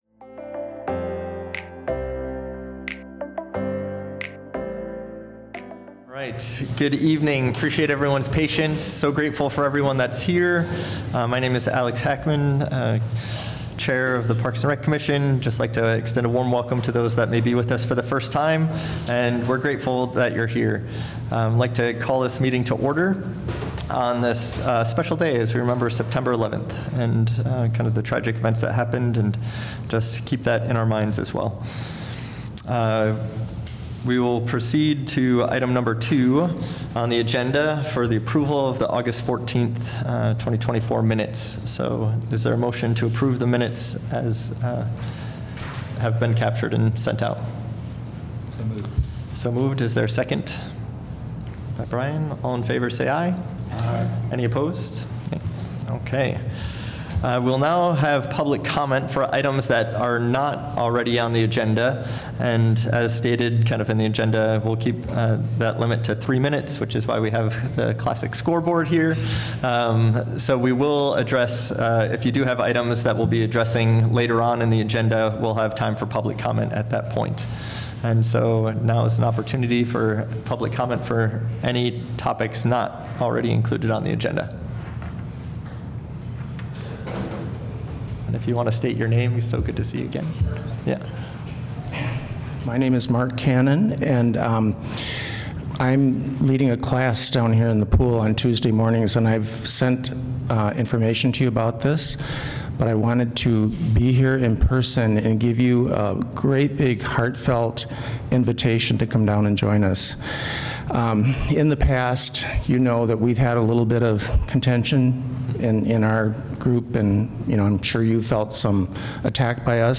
A meeting of the City of Iowa City's Parks and Recreation Commission.